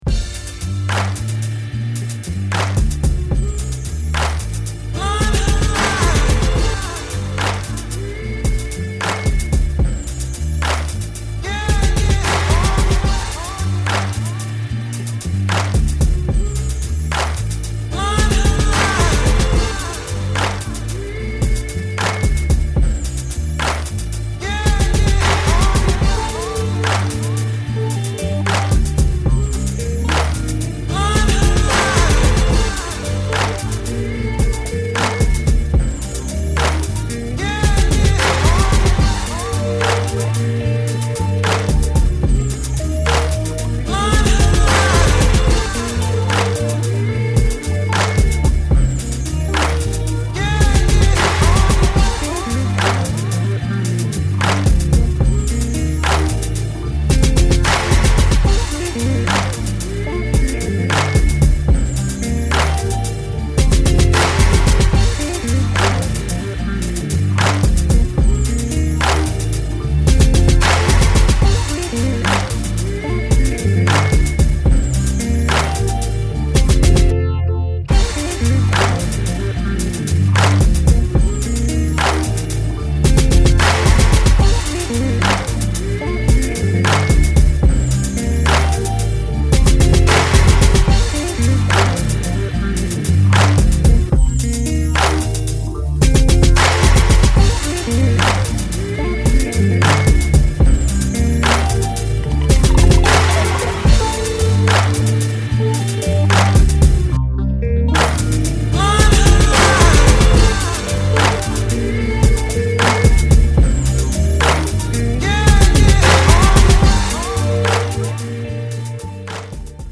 They offer up the free beat you’ll find below.